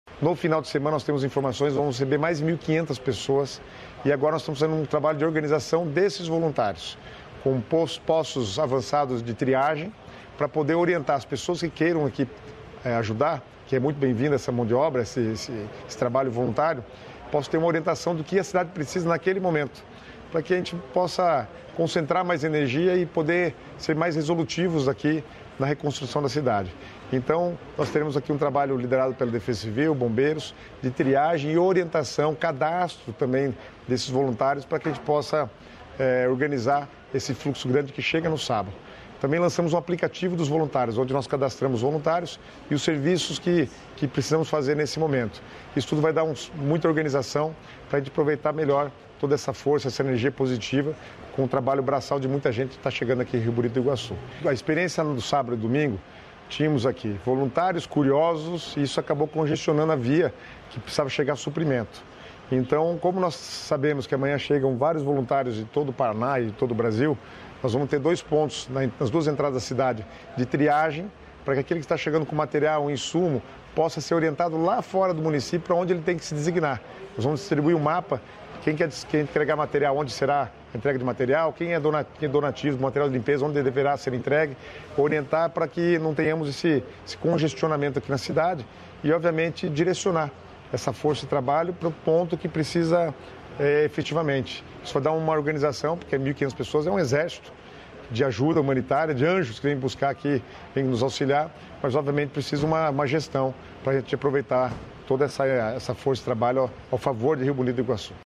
Sonora do secretário de Estado das Cidades, Guto Silva, sobre o recebimento de mil voluntários em Rio Bonito do Iguaçu